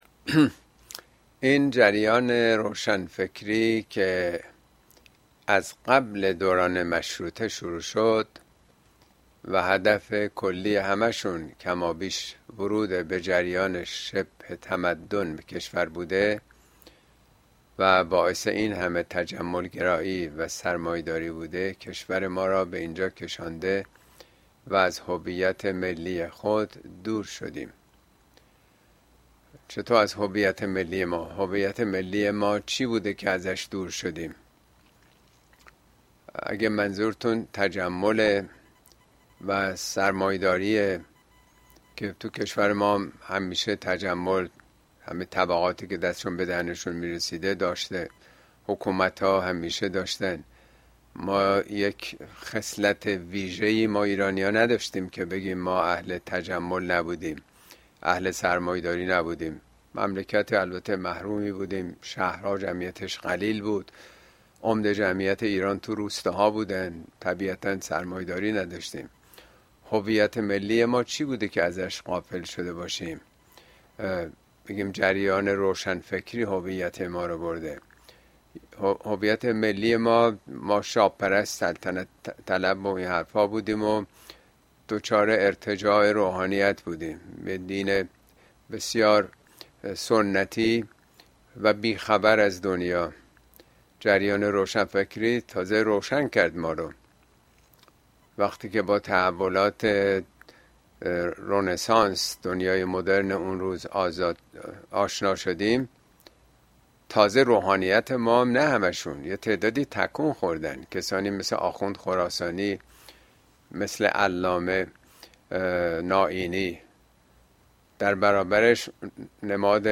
` موضوعات اجتماعى اسلامى ۱۱ سپتامبر و سرانجامش اين سخنرانى به تاريخ ۱۱ سپتامبر ۲۰۲۴ در كلاس آنلاين پخش شده است توصيه ميشود براىاستماع سخنرانى از گزينه STREAM استفاده كنيد.